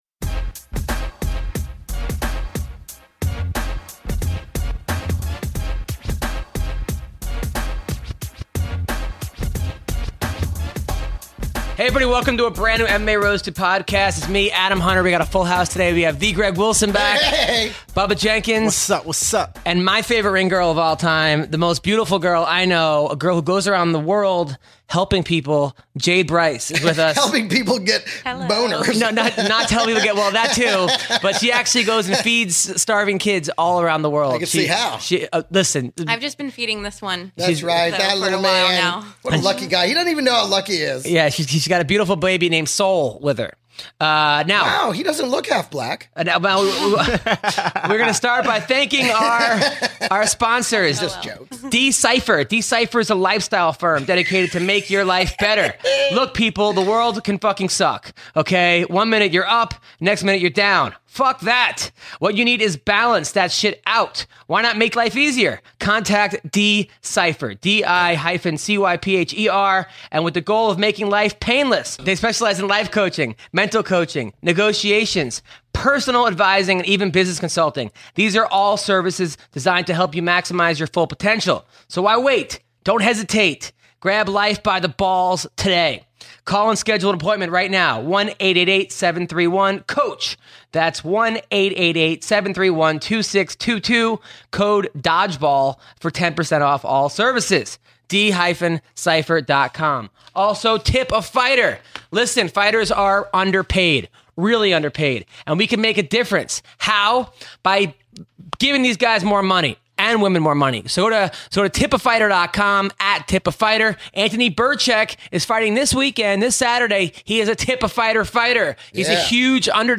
in studio for the latest in MMA.